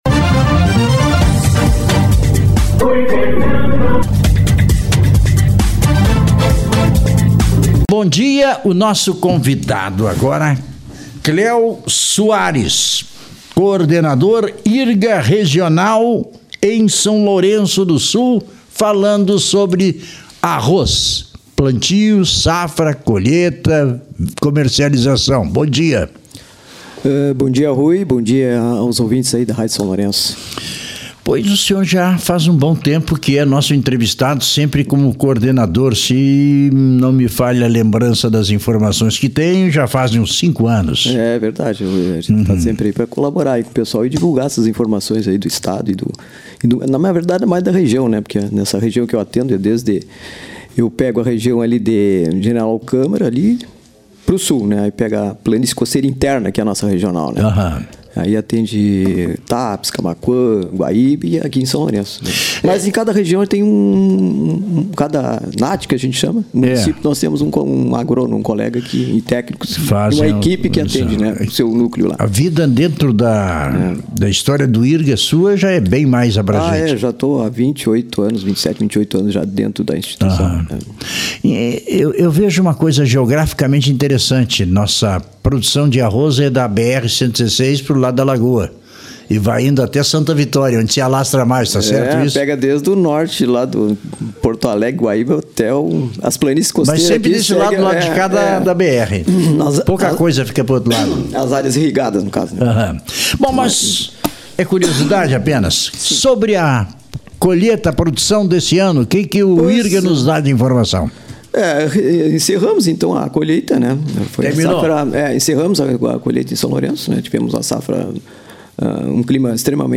Na oportunidade, recomendou sobre o manejo do solo (rotação de culturas) e, em relação ao preço: compra e procura – acompanhe a entrevista: